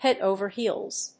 アクセントhéad òver héels